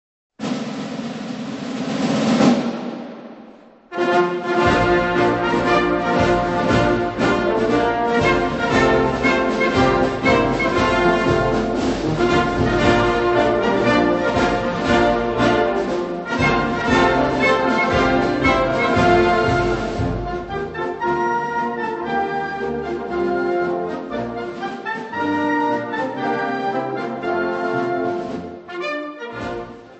PAR LE CHOEUR DU RÉGIMENT DE FRIBOURG
Chant de guerre helvétique